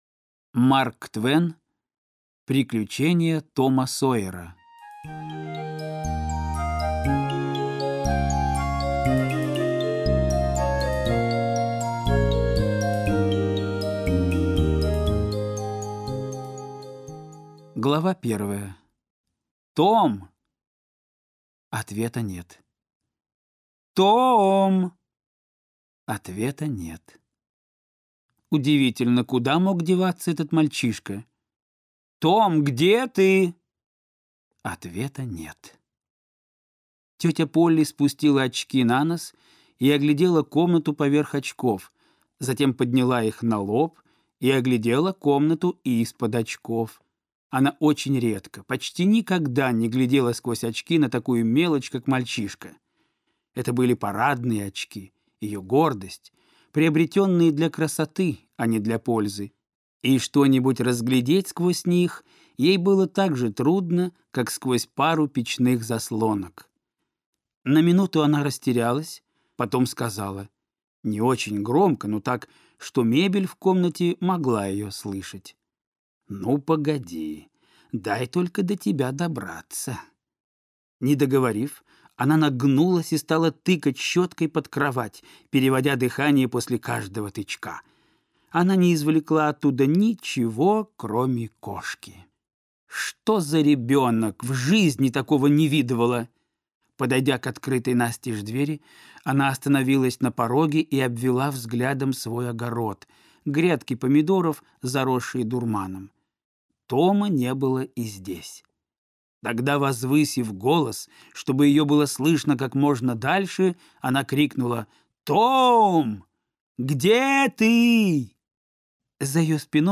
Аудиокнига Приключения Тома Сойера - купить, скачать и слушать онлайн | КнигоПоиск